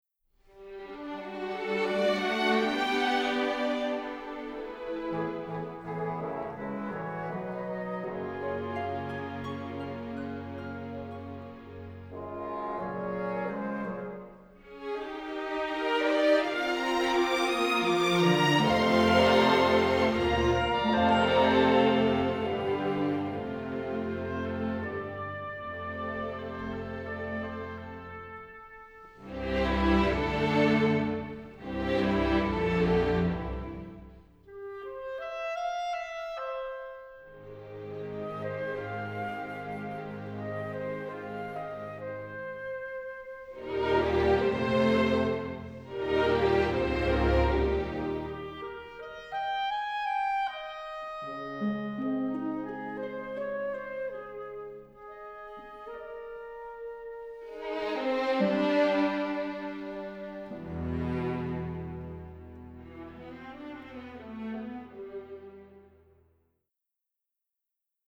crisp recording